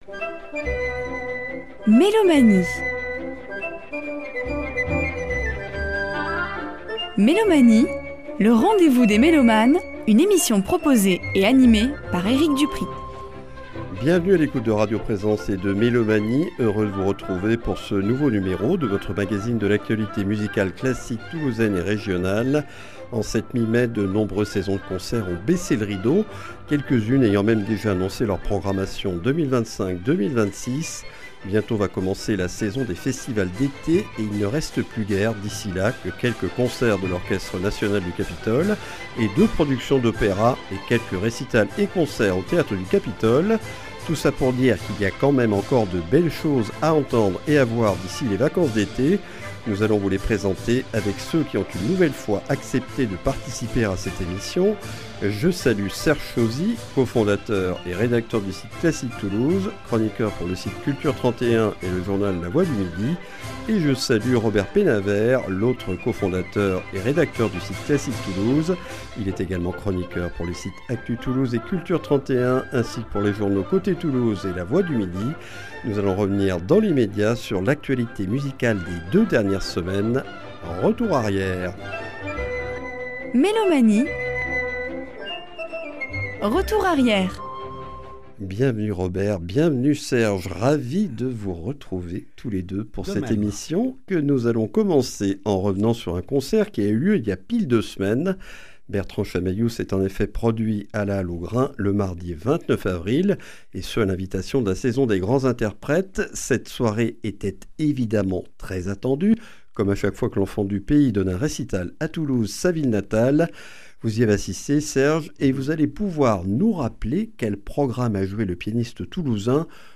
Mélomanie(s) et ses chroniqueurs présentent l’actualité musicale classique de notre région.